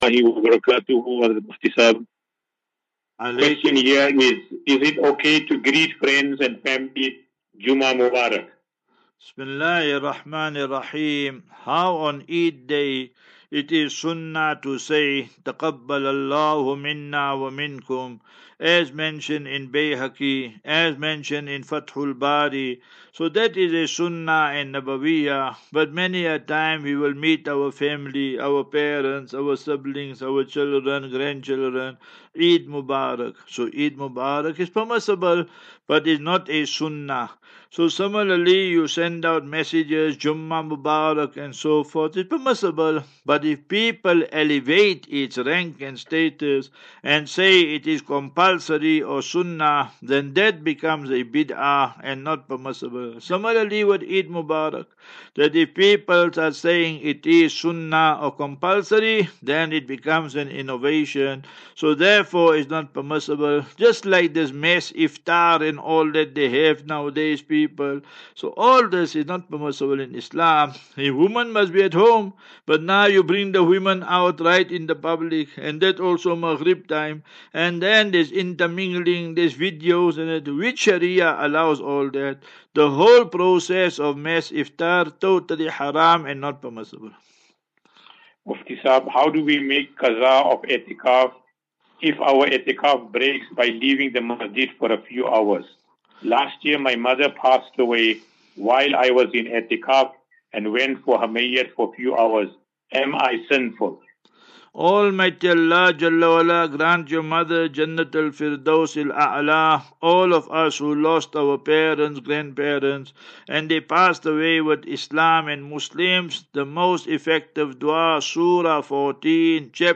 As Safinatu Ilal Jannah Naseeha and Q and A 29 Mar 29 March 2024.